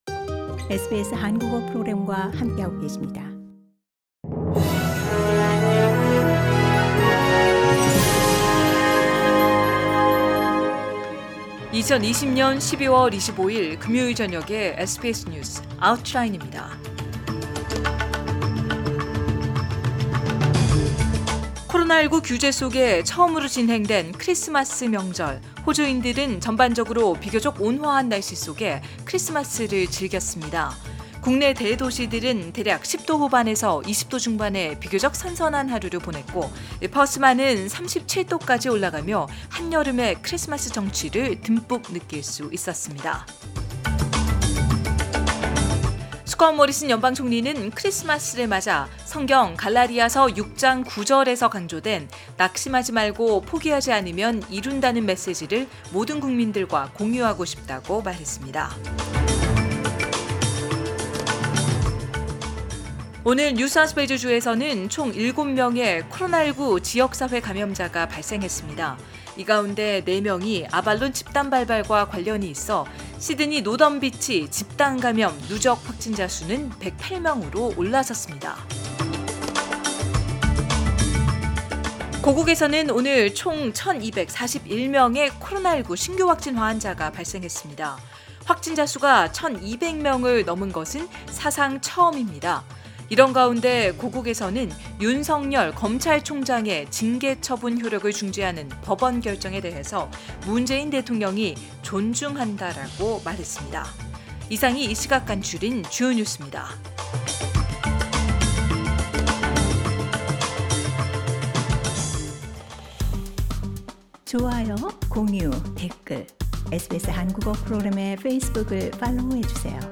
2020년 12월 25일 금요일 저녁의 SBS 뉴스 아우트라인입니다.